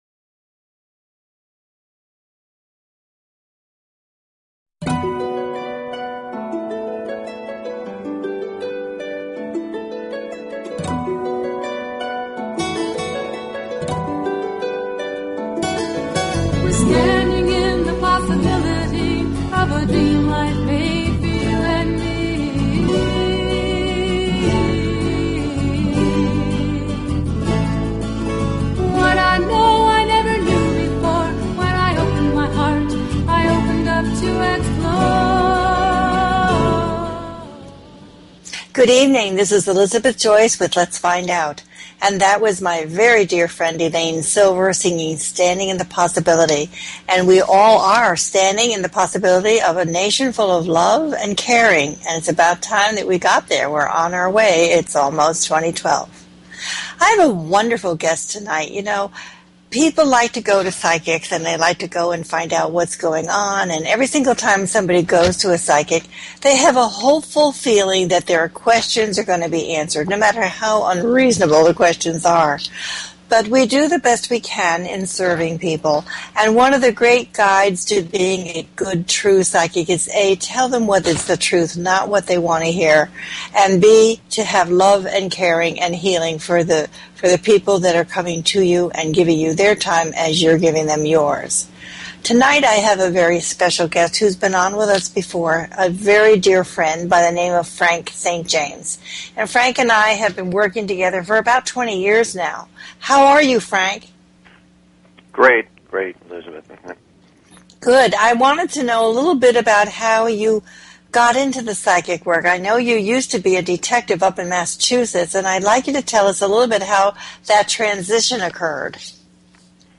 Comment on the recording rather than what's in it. This is a call in show, so have your first name, birthday, and focused question ready. The show's information explains how a psychic gets their information and arrives at a prediction.